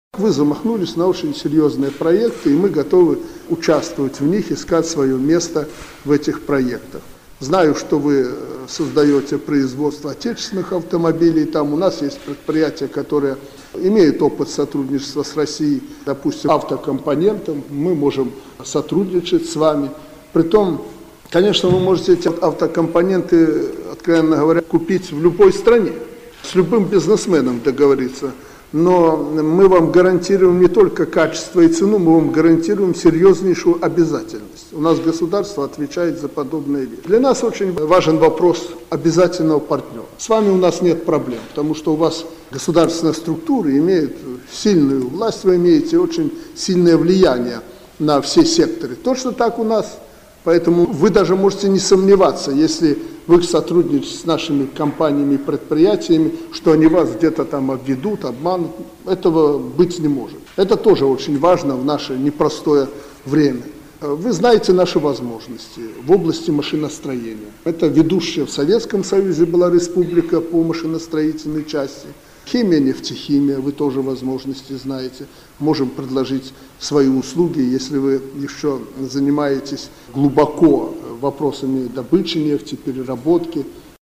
Это Президент Беларуси Александр Лукашенко заявил на встрече с главой Чеченской Республики Рамзаном Кадыровым. Производственная кооперация - наиболее развитая, взаимовыгодная форма сотрудничества, которая позволяет обеспечить потребности регионального рынка, создать высокотехнологичные рабочие места.